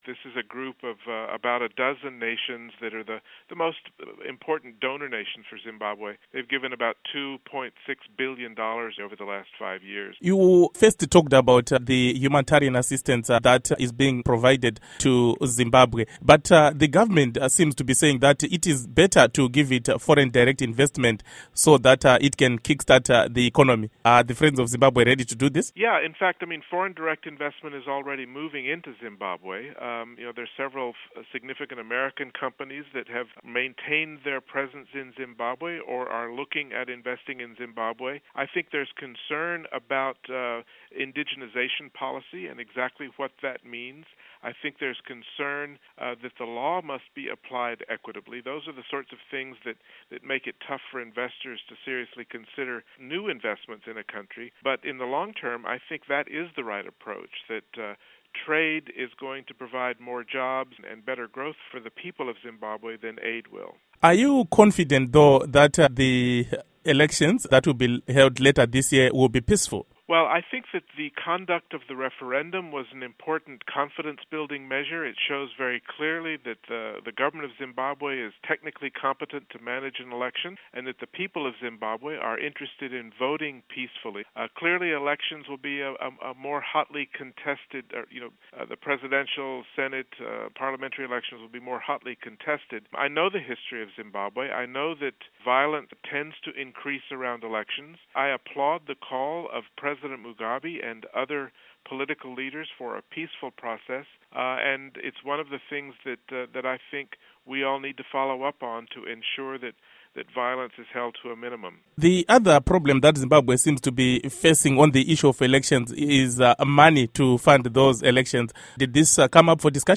Interview With Bruce Wharton